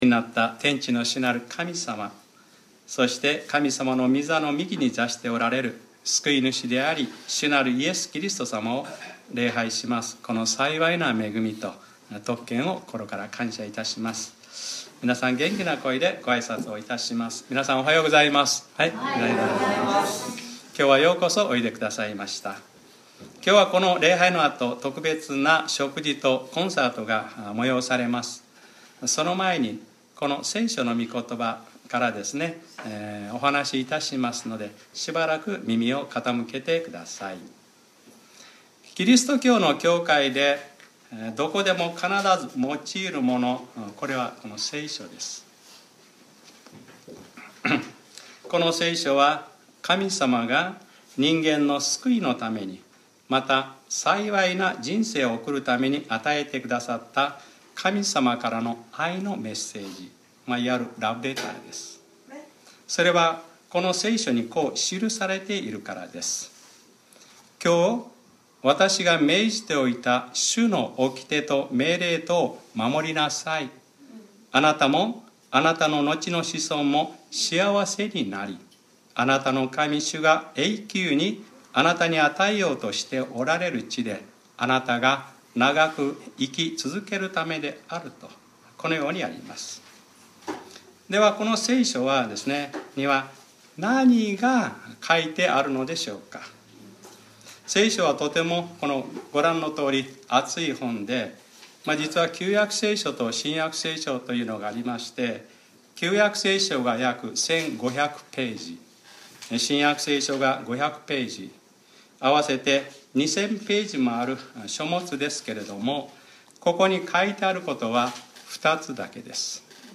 2019年06月16日（日）礼拝説教『人がひとりでいるのはよくない』